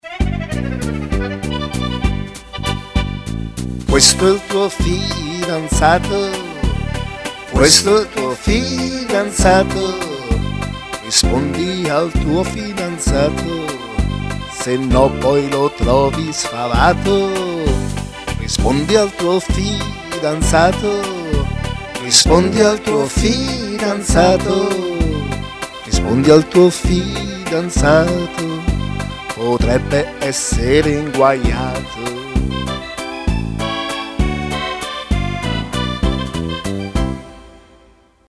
Una suoneria personalizzata che canta il nome